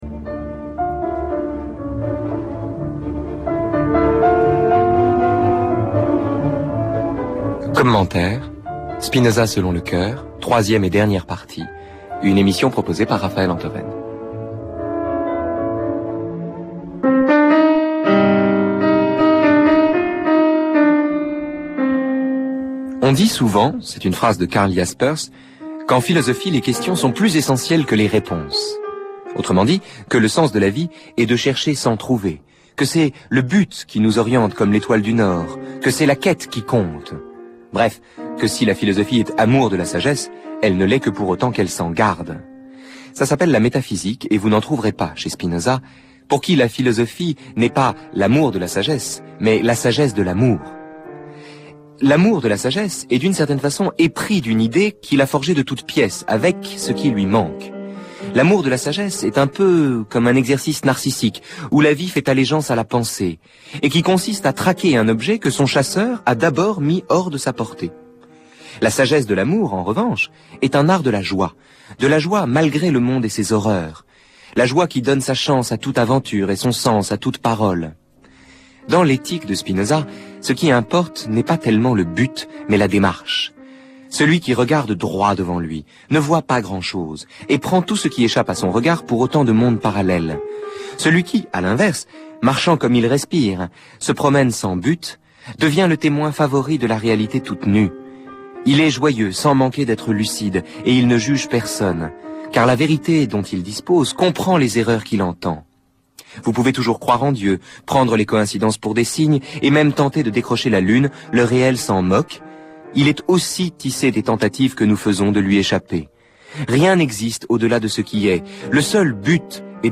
En ces périodes de confinement, où la paresse devient inexcusable, nous vous proposons la troisième partie des trois conférences (audio) sur l'Ethique de Spinoza.